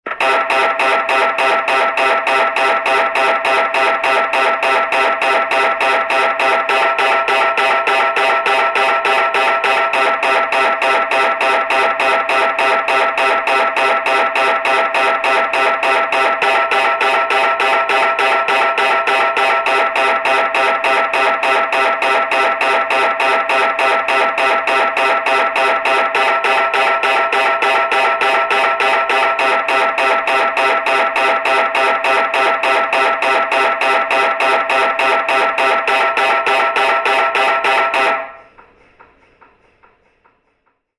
На этой странице представлены звуки работы аппарата МРТ – от ритмичных постукиваний до гудения разной интенсивности.
Во время МРТ сканирования слышны разные звуки, включая этот